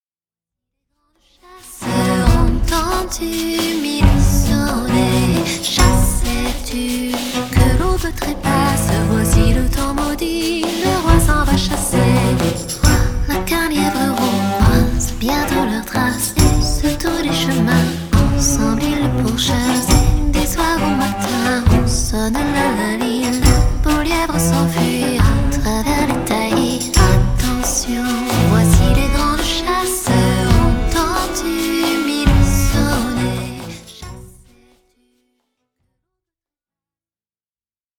harpiste chanteuse